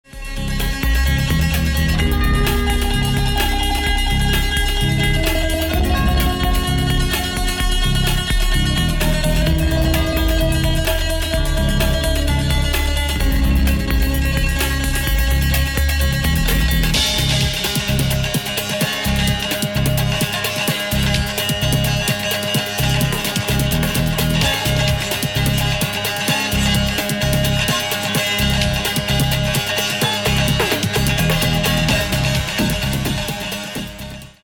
t´s about world music, electronic break beats and more…
guitar, sitar, synthesizer, electronics